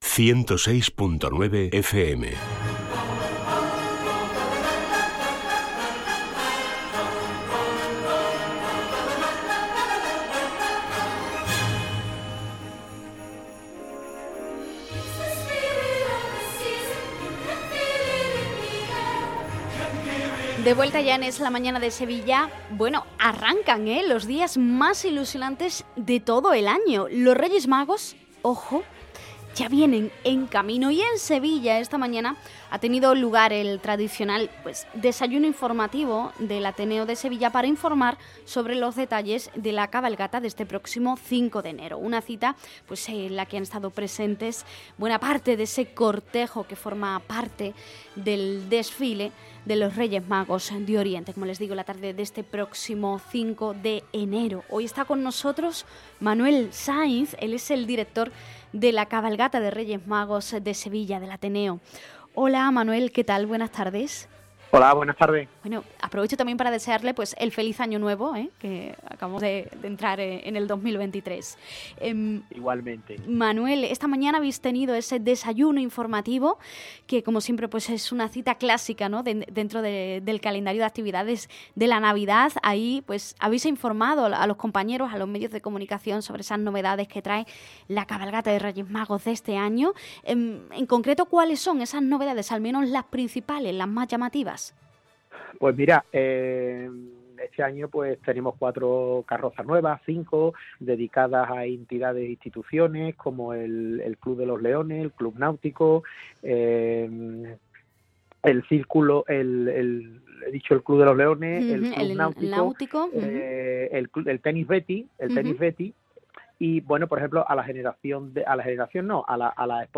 Desayuno con los medios de comunicación en La Raza
Entrevista